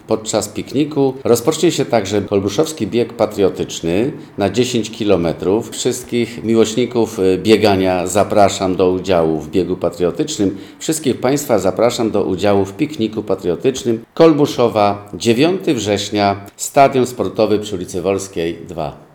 Na wydarzenie zaprasza burmistrz Kolbuszowej, Jan Zuba.